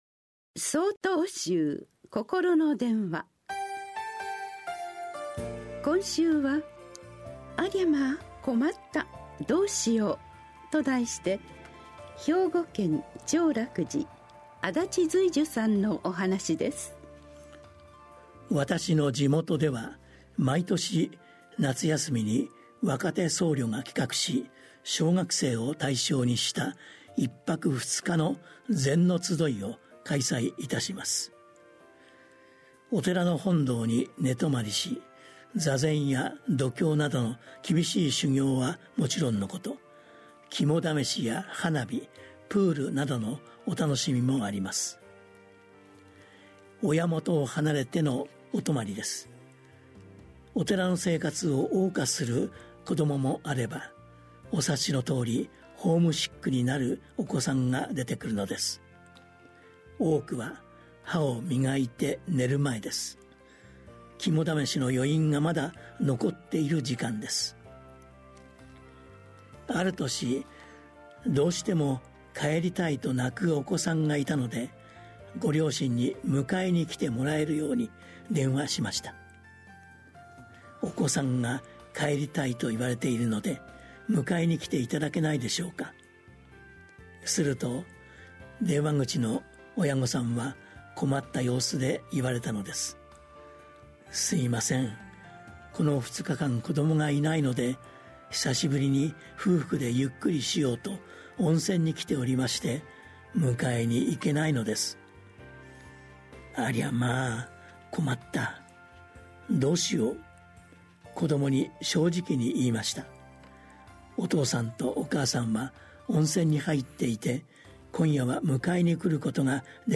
心の電話（テレホン法話）８/19公開『ありゃま～、困った。どうしよう』 | 曹洞宗 曹洞禅ネット SOTOZEN-NET 公式ページ